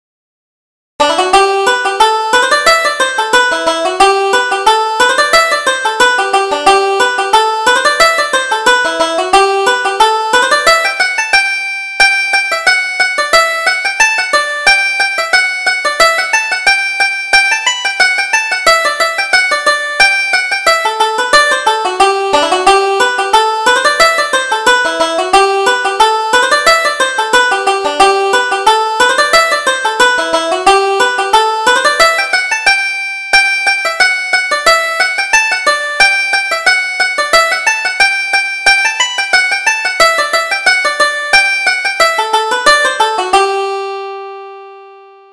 Reel: The Bouncing Boy